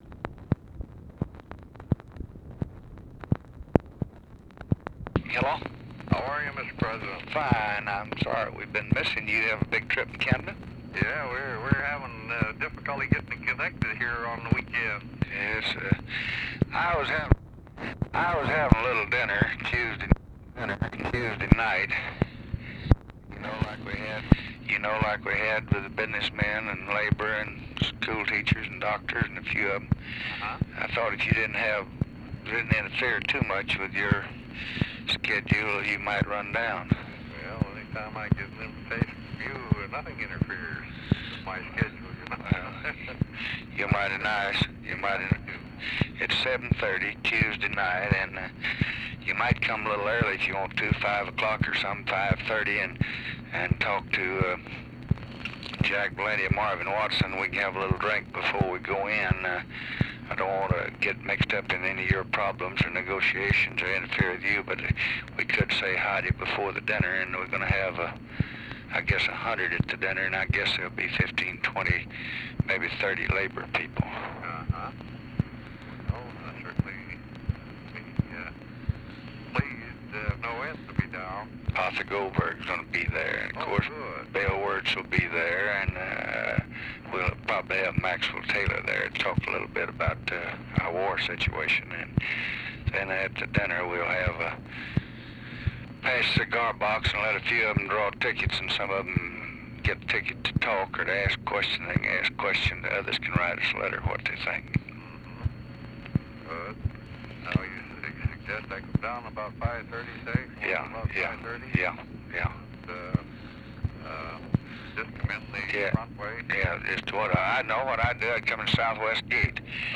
Conversation with I. W. ABEL, August 14, 1965
Secret White House Tapes